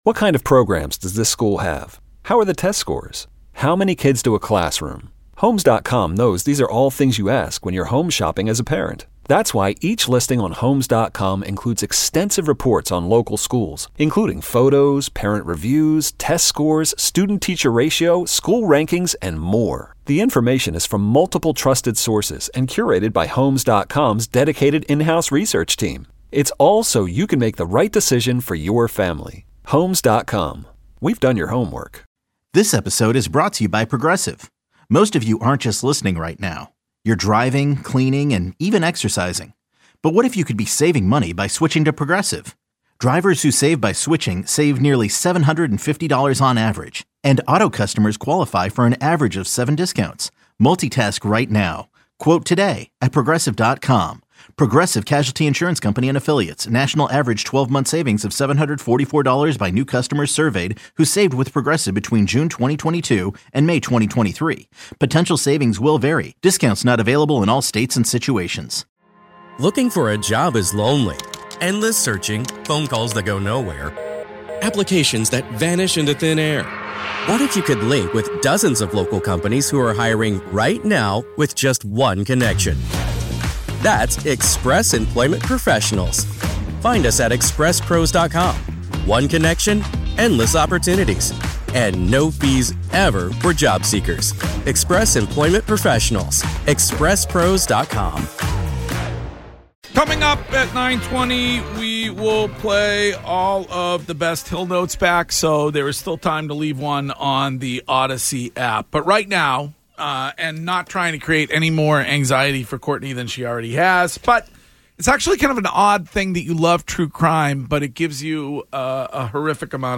the documentary producers join in studio